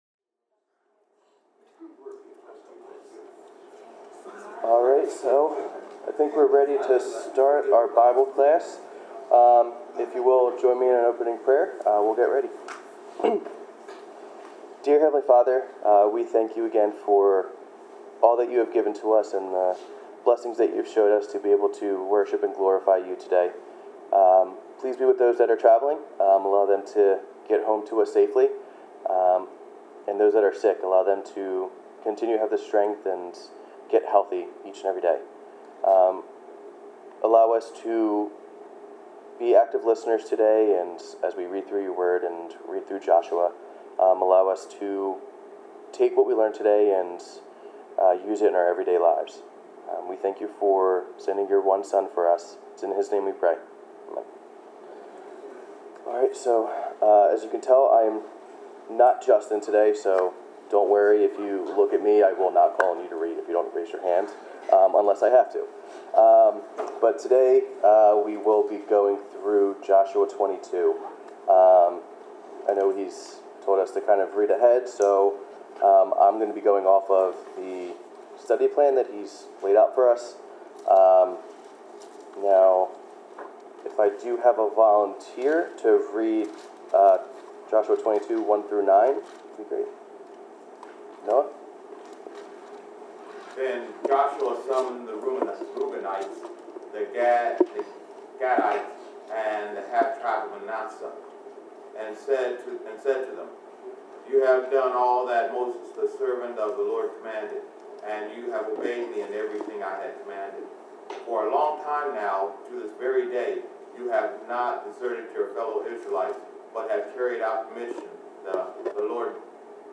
Bible class: Joshua 22
Service Type: Bible Class Topics: Disagreements among God's People , Faith , Holiness , Obedience , Praising God , Promises of God , Understanding